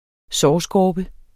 Udtale [ ˈsɒː- ]